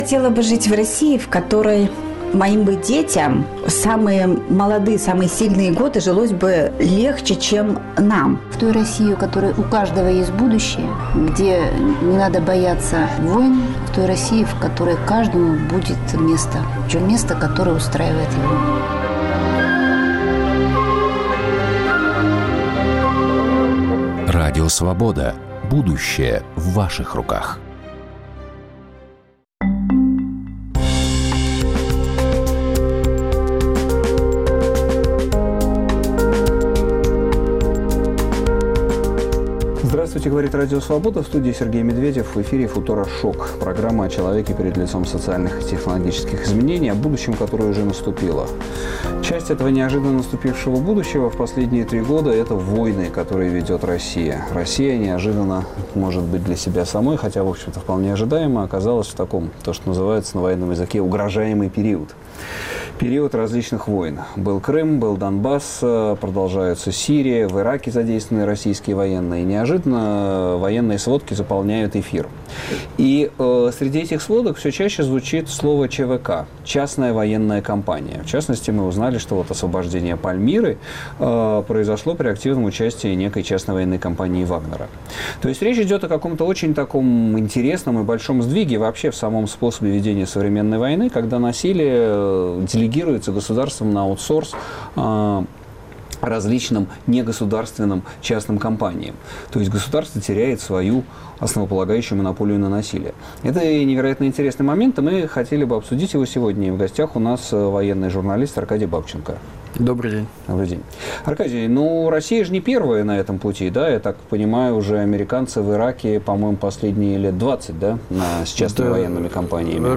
Война на аутсорсе: с кем воюют частные армии? Гость: Аркадий Бабченко, журналист